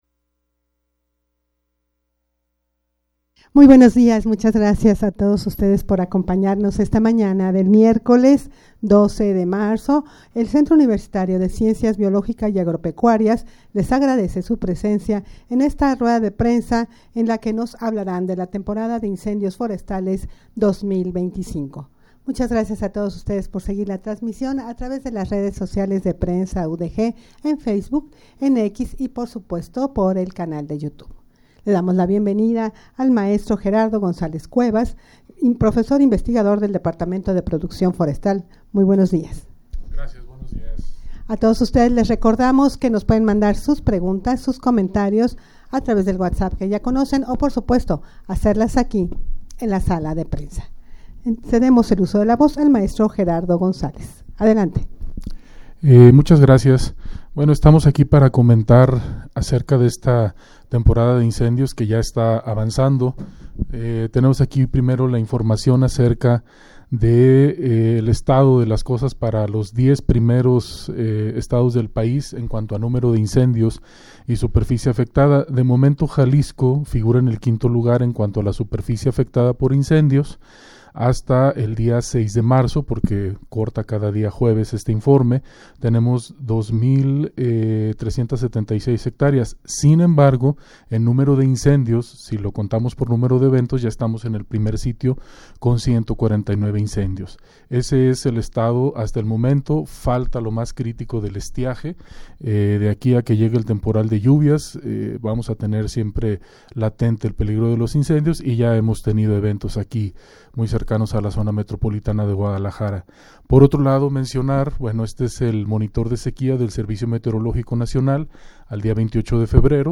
Audio de la Rueda de Prensa
rueda-de-prensa-acciones-de-proteccion-contra-los-incendios-forestales.mp3